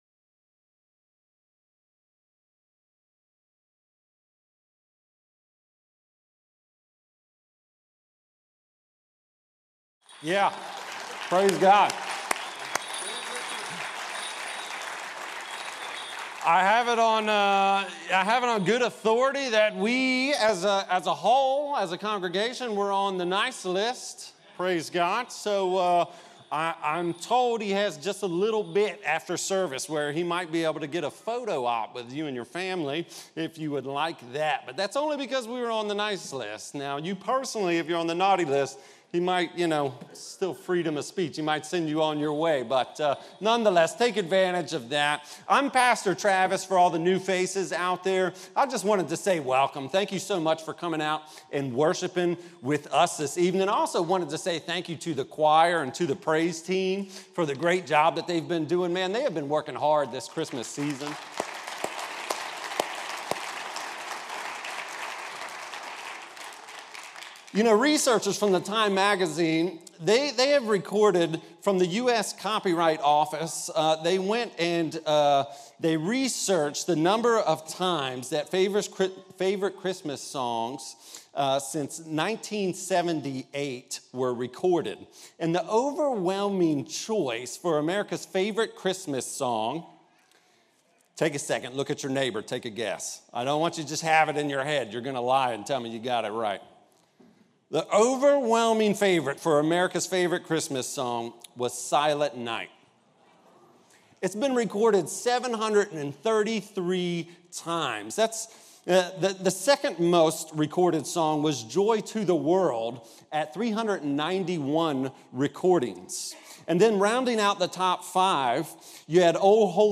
Sermons | Battlefield Baptist Church